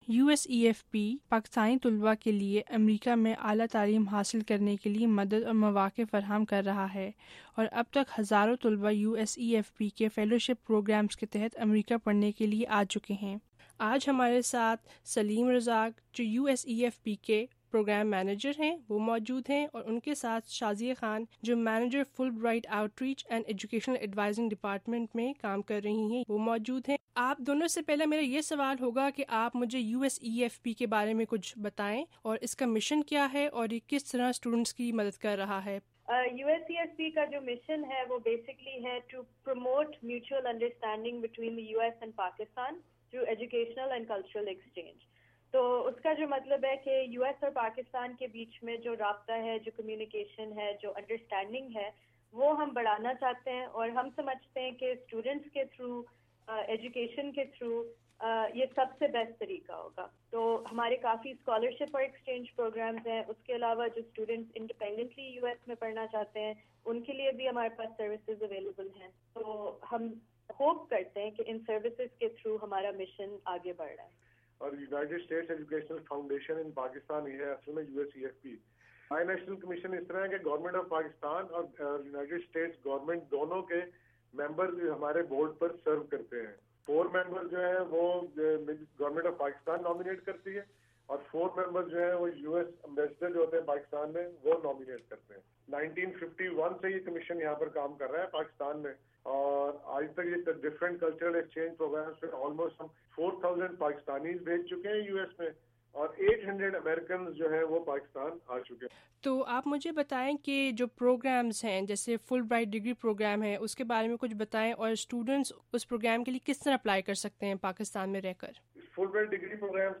’یو ایس اِی ایف پی‘ انٹرویو